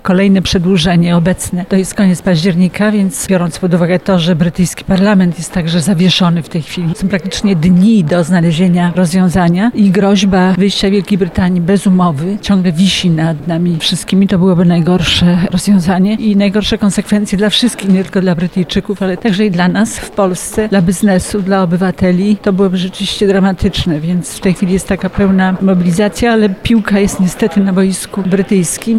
Danuta Hübner (na zdjęciu) gościem specjalnym trwającego w Lublinie Kongresu Inicjatyw Europy Wschodniej. Poseł Parlamentu Europejskiego mówiła o przyszłość Europy w cieniu brexitu.